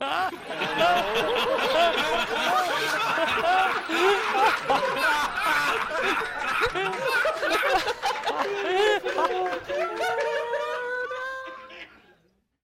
laugh2.mp3